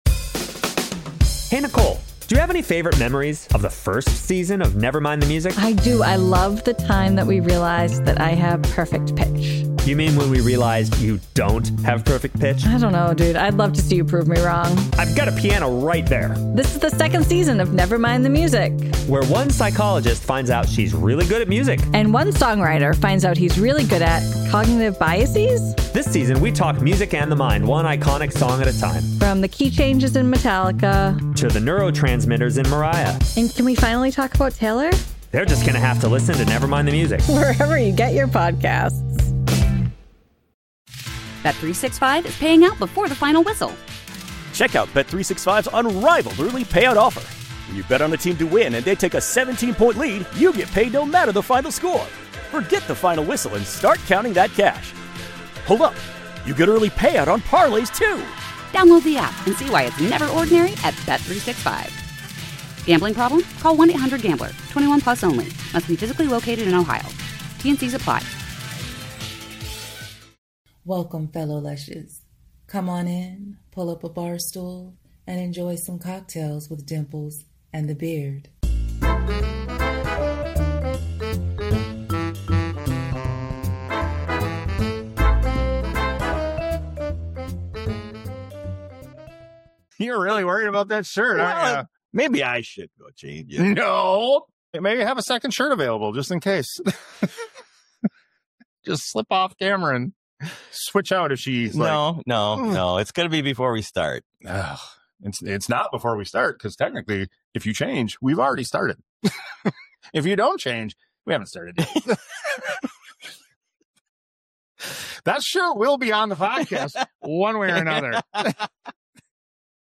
On this episode we sit down with singer-songwriter